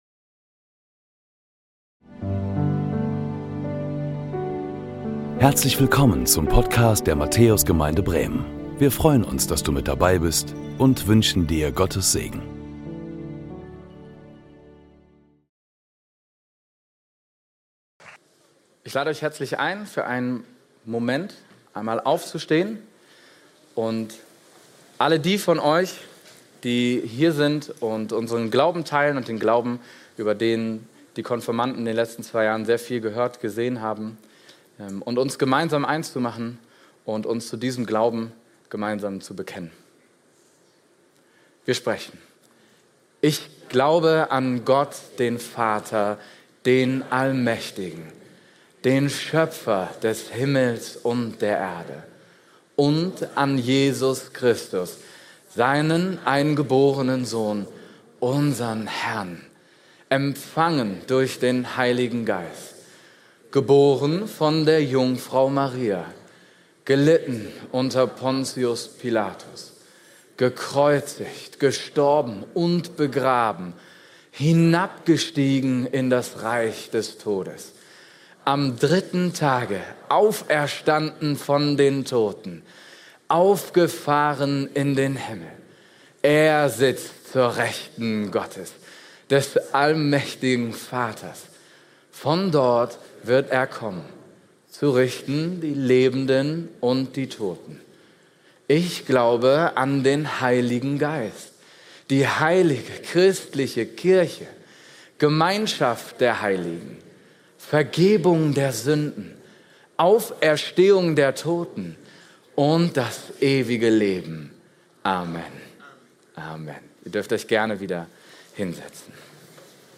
Glückliche Augen – Konfirmationsgottesdienst – Matthäus-Gemeinde Podcast
Predigten